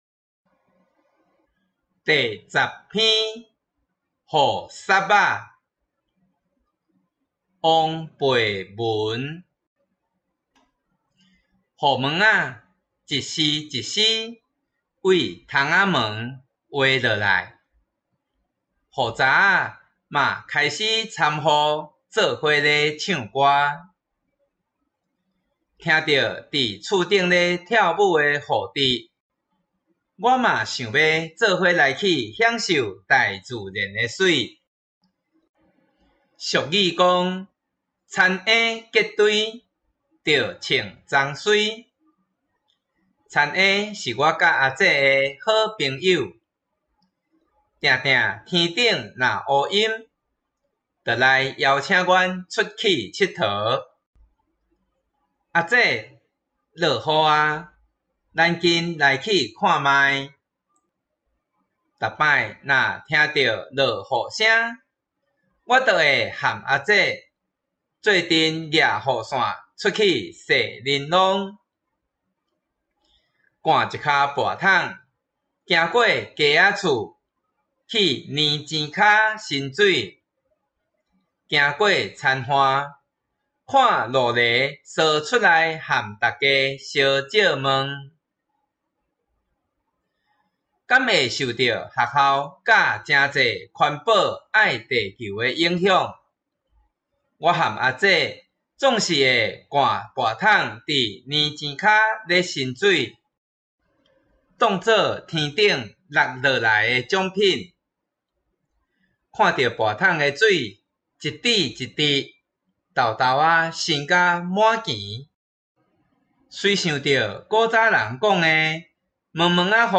114臺灣台語朗讀音檔3-雨霎仔.m4a